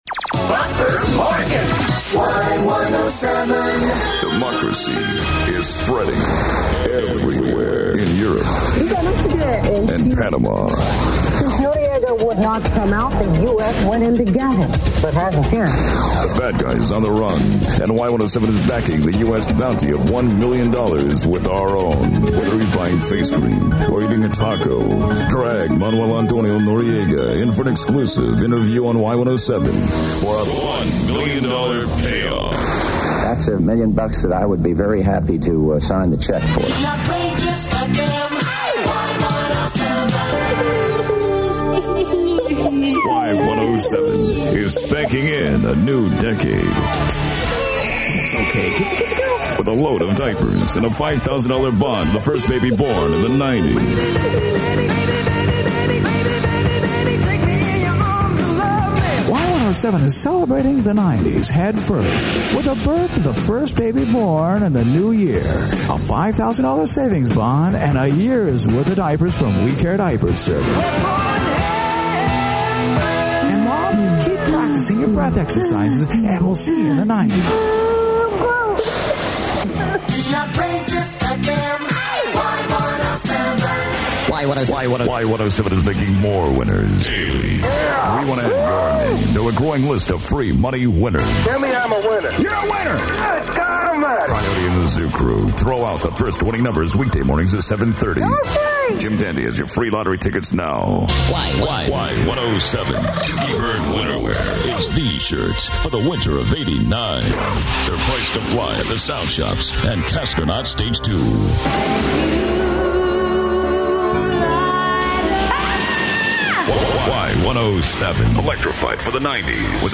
Side A: Promos & Stuff
A note on "Bad Attitude": All celebrity voices are impersonations done by local talent. (Celebrity parts were done to a click track and added to the music later!) All parodies were produced from scratch.